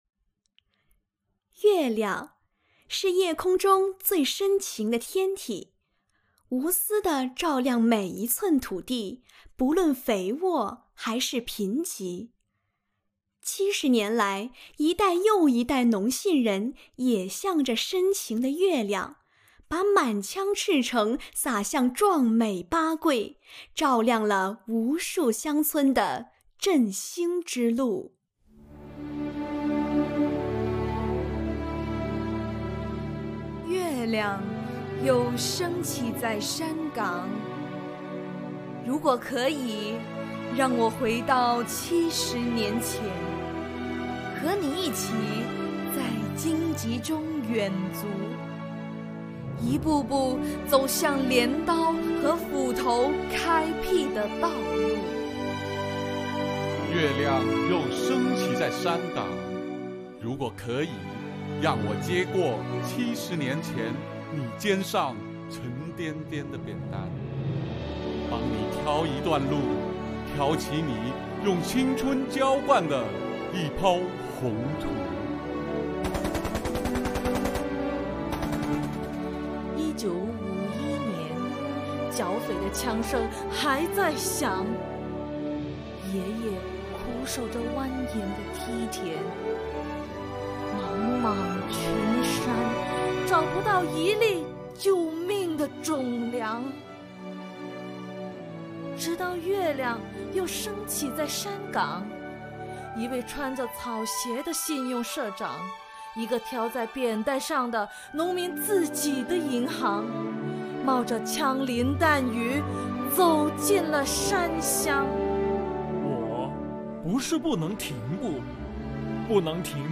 - 主题诵读作品 -